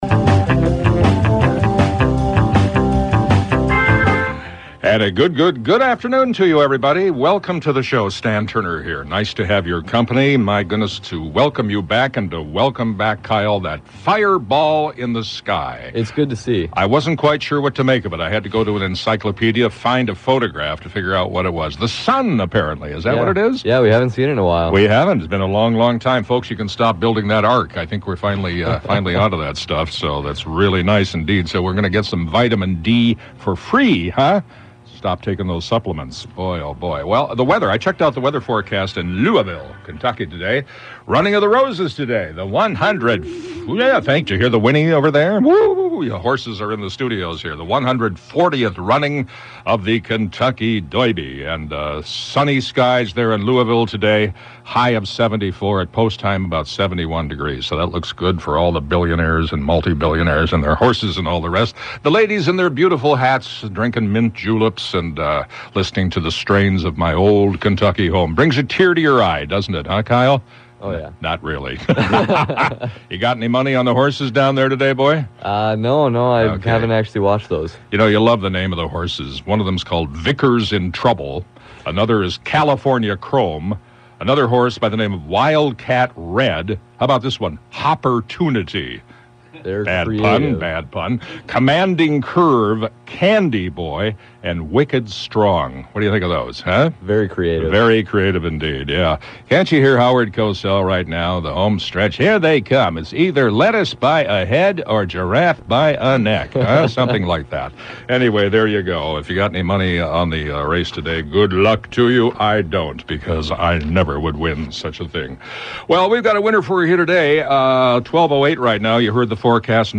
Today we have some of the cast from the new History Theatre show "The Working Boys Band" set in the early 1900's Minneapolis. Based on actually events, music saves some working class boys from a lot of trouble.